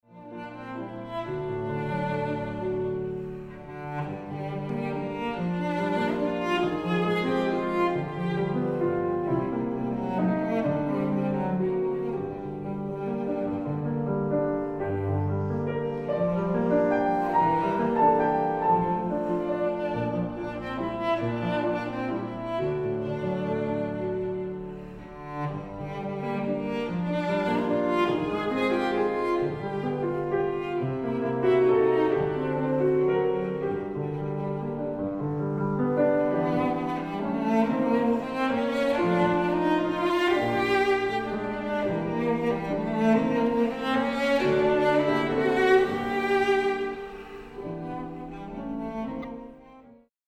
大提琴
鋼琴
使用骨董真空管麥克風錄音，並以自製的真空管混音台混音